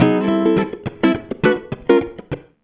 104FUNKY 10.wav